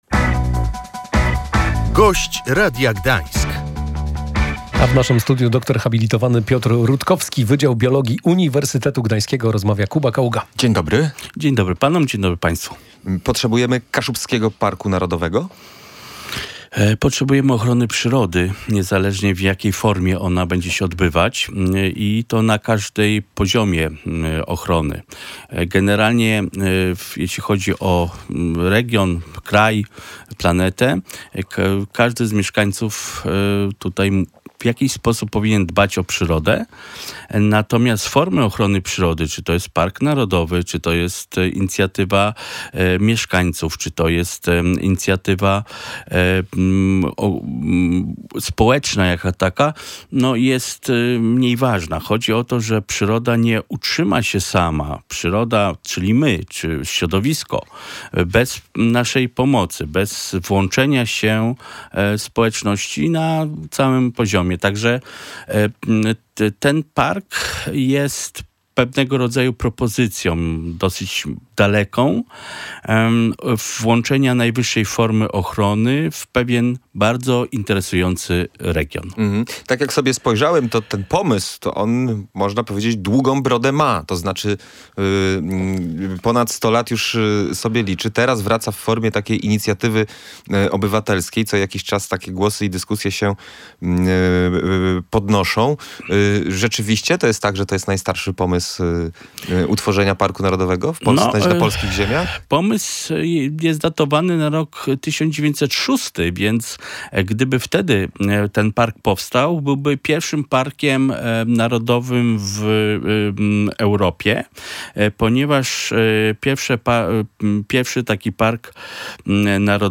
PROJEKT ZMIAN W SPRAWIE REZERWATÓW W rozmowie poruszony został też pomysł zmian w prawie dotyczących rezerwatów.